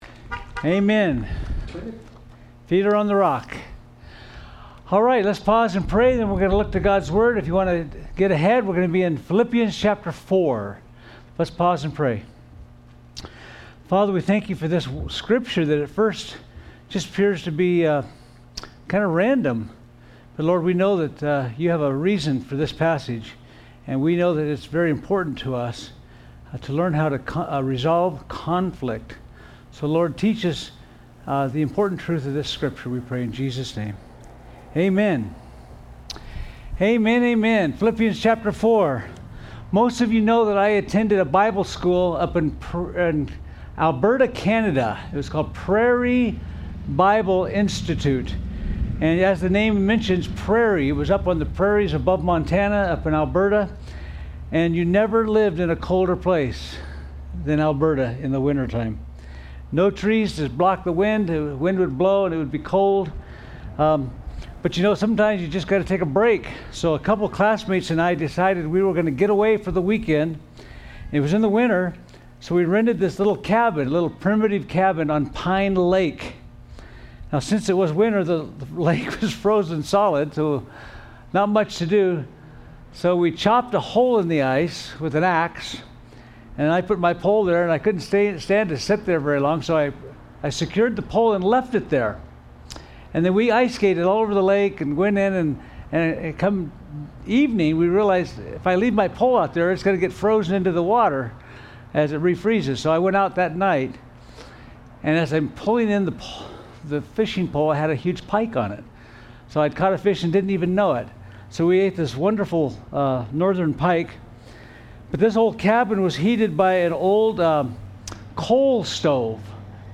Sermons
Drive-In Service